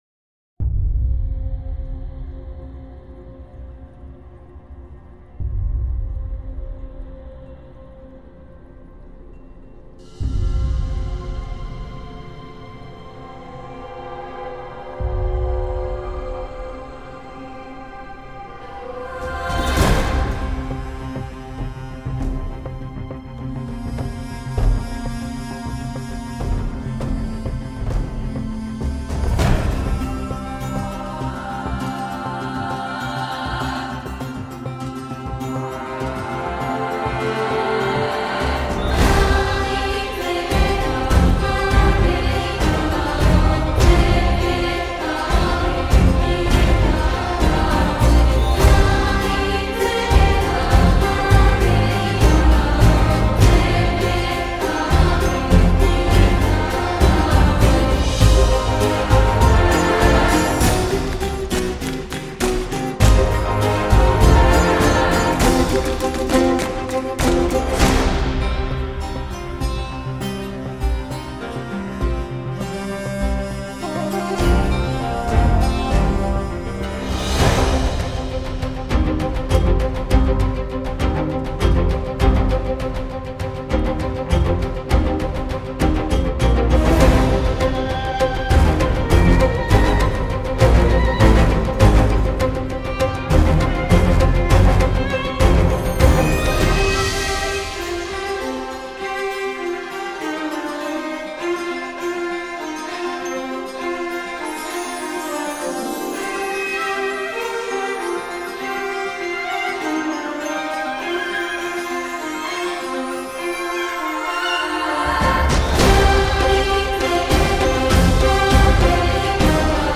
使用传统巴尔干乐器的管弦乐录音
• 使用高端录音设备和自定义卷积混响录制两个麦克风位置（闭孔）
• 来自巴尔干半岛的40多位最佳民间乐器演奏者（管乐器，弓，弦乐，铜管乐器，声乐四重奏，打击乐器）
• 精心采样的巴尔干铜管乐队